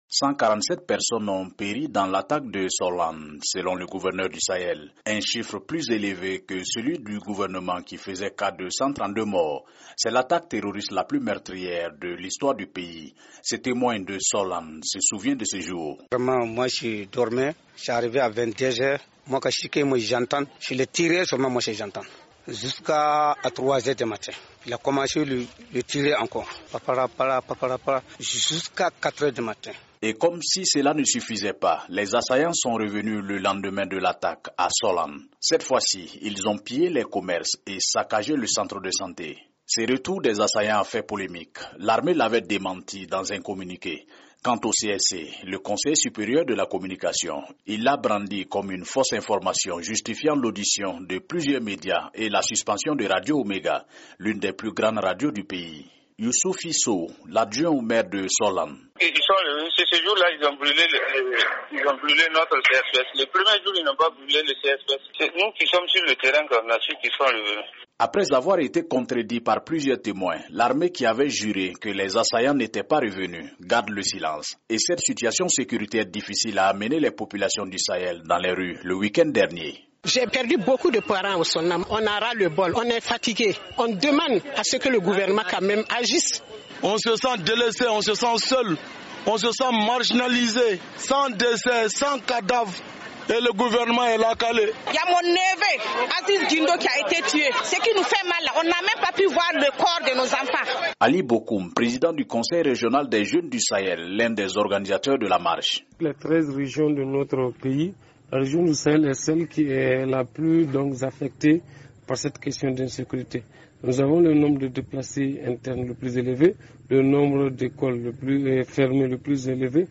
Une attaque qui fait polémique tant sur le nombre de victimes que sur un éventuel retour des assaillants quelques heures après la première attaque. Un reportage de notre correspondant